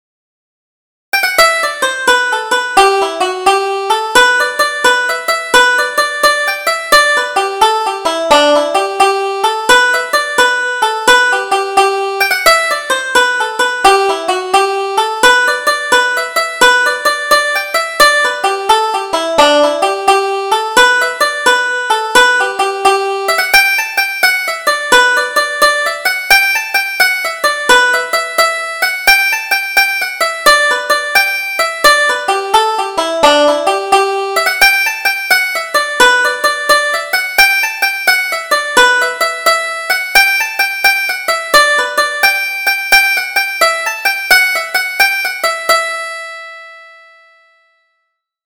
Double Jig: Happy to Meet and Sorry to Part